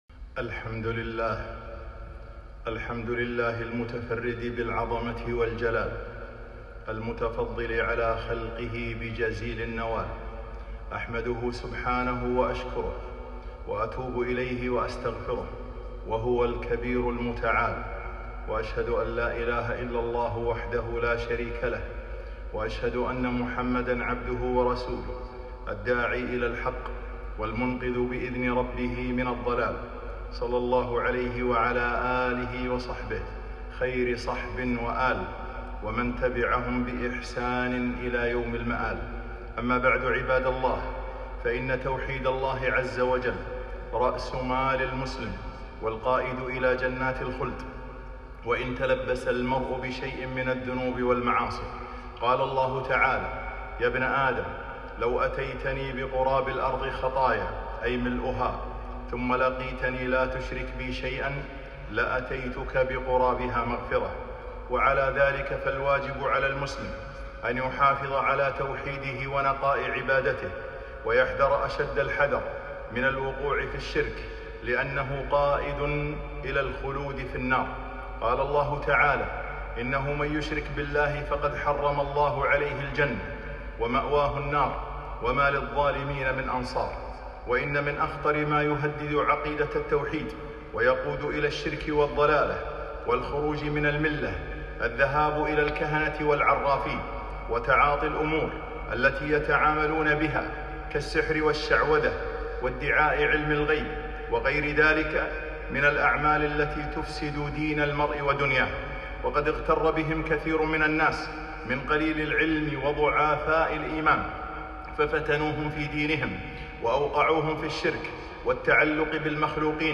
خطبة - خطبة التحذير من أعمال الكهان